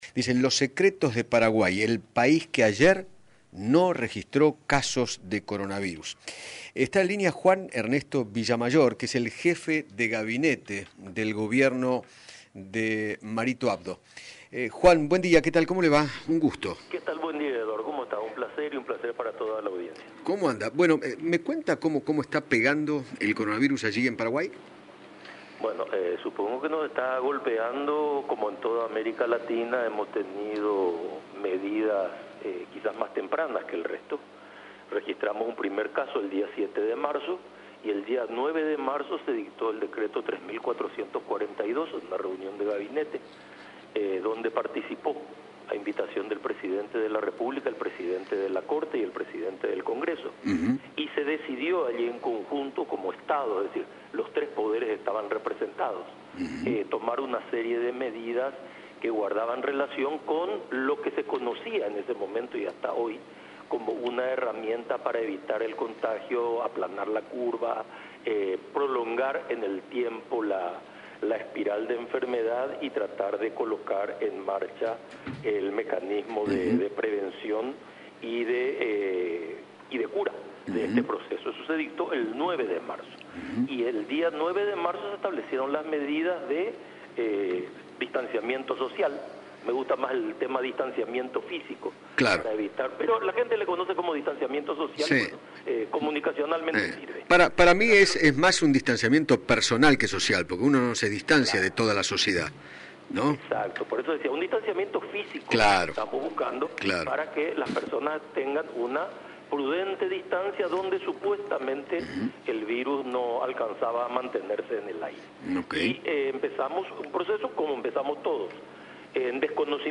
Juan Ernesto Villamayor, Jefe de Gabinete de Paraguay, dialogó con Eduardo Feinmann sobre las tempranas medidas que adoptó el Gobierno de Mario Abdo Benítez para frenar el avance del Coronavirus y se refirió a la noticia esperanzadora del día de ayer: su país no registró nuevos casos.